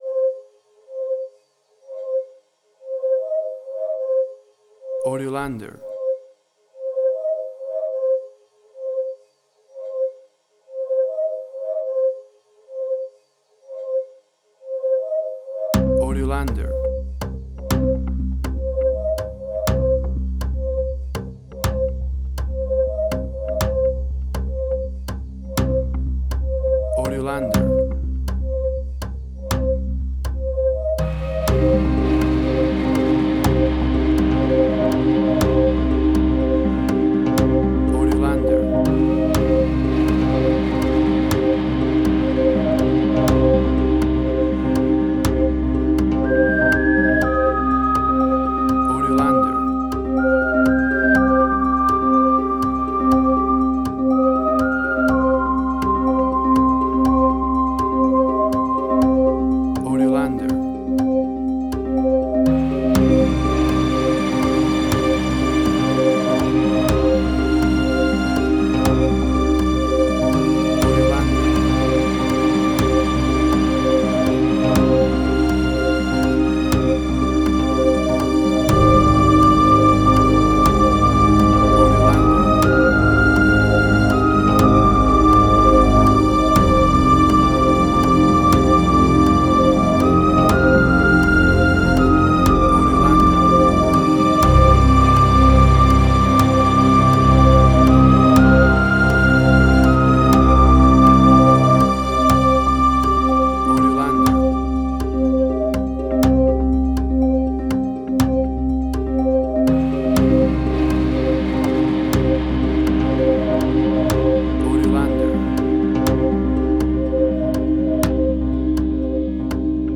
Spaghetti Western, similar Ennio Morricone y Marco Beltrami.
Tempo (BPM): 123